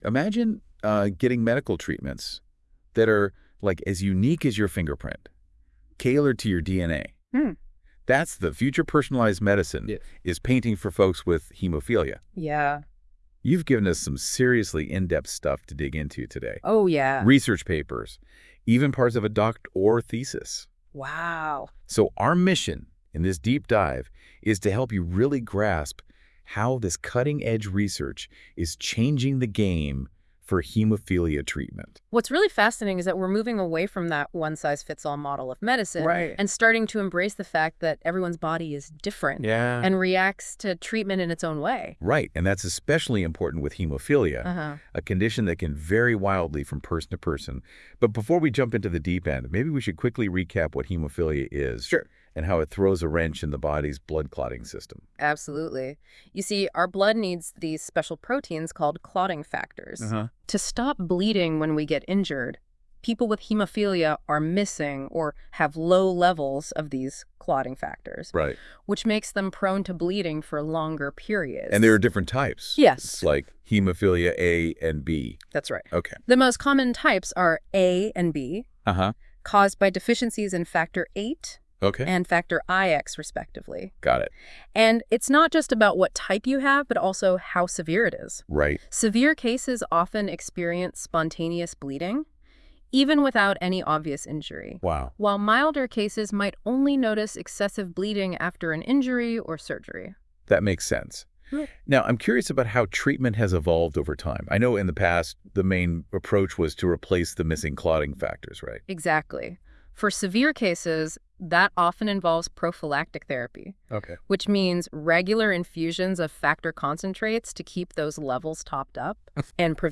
With AI a podcast is generated automatically from the thesis.